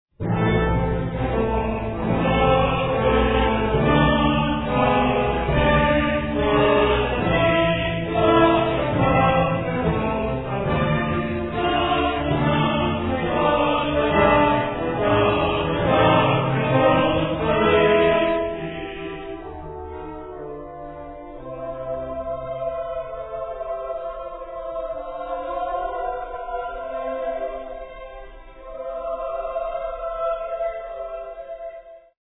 Solo Quartet, Chorus and Orchestra
10 CHORUS: